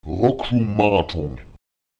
Die Hauptbetonung eines solchen áton-Substantivs verlagert sich stets auf die vorletzte Silbe át: